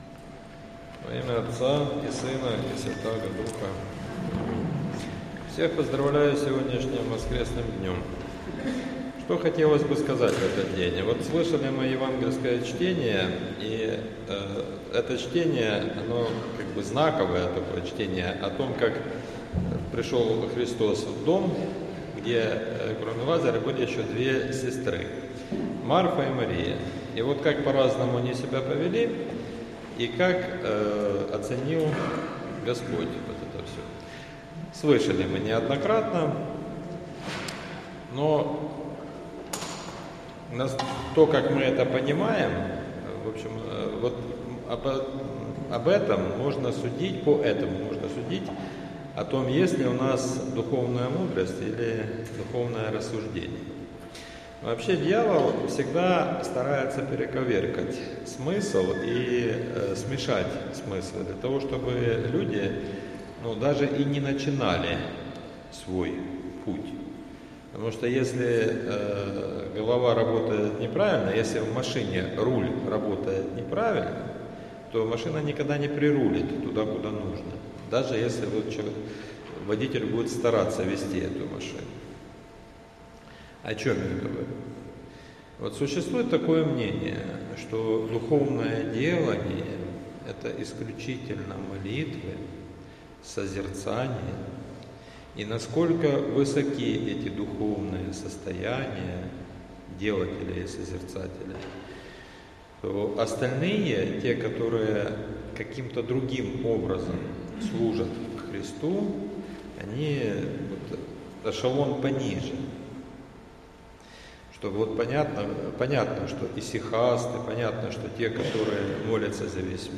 Проповедь в девятую неделю по Пятидесятнице — Спасо-Преображенский мужской монастырь